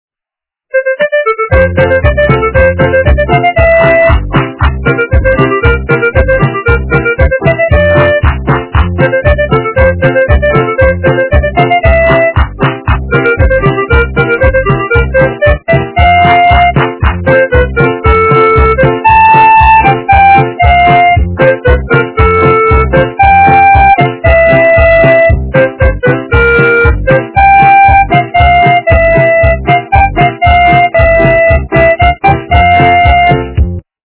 - фильмы, мультфильмы и телепередачи
качество понижено и присутствуют гудки